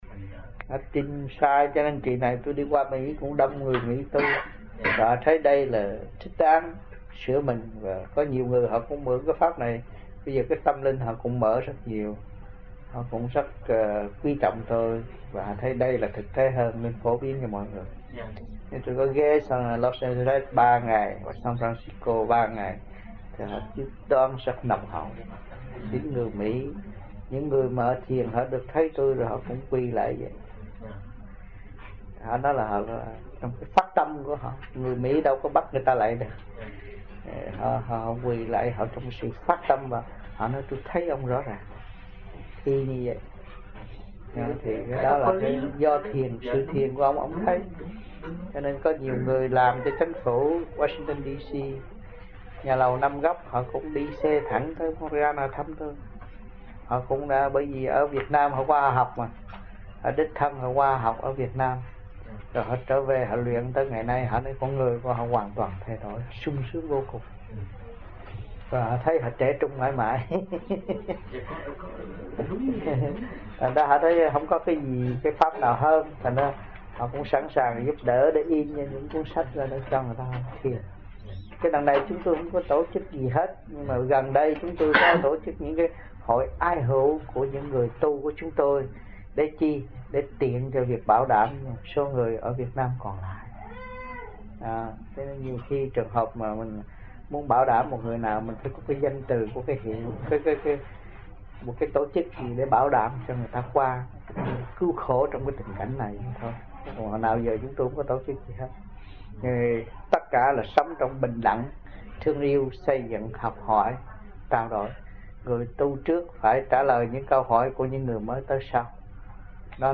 1980-11-20 - AMPHION - THUYẾT PHÁP 07